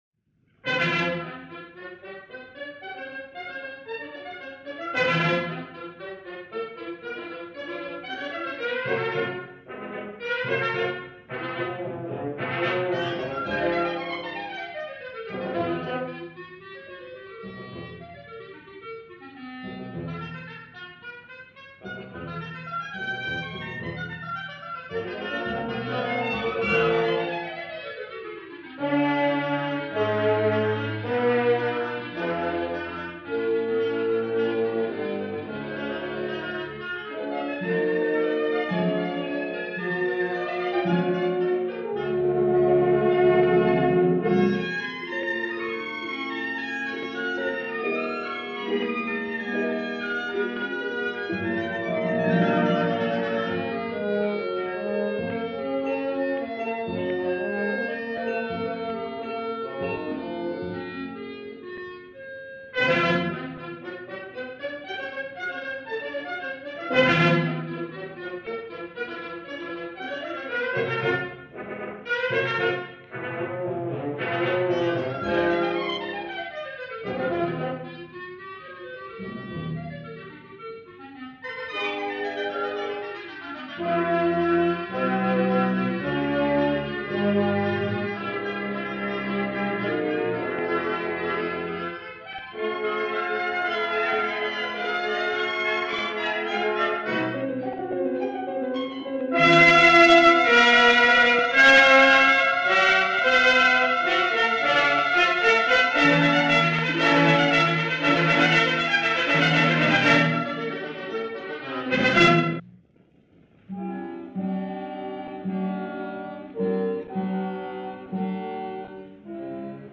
tenor
This radio broadcast recording is from February 13, 1952.